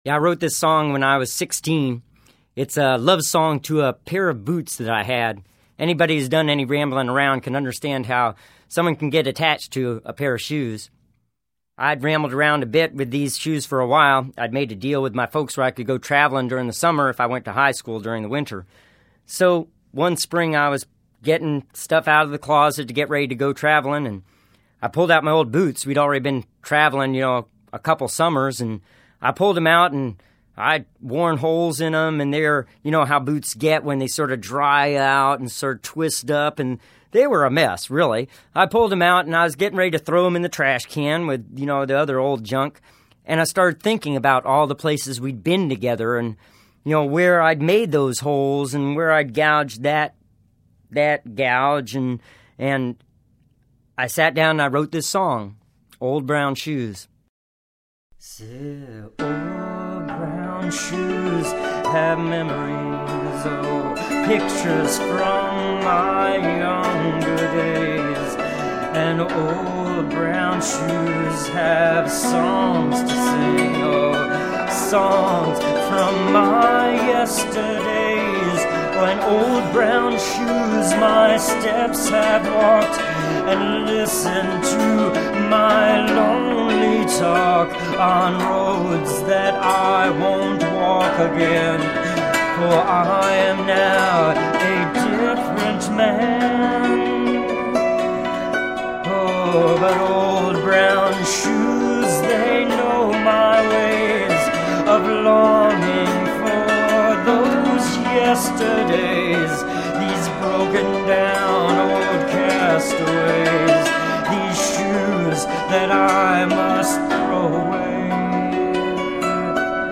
Solo Vocals and Acoustic Dulcimer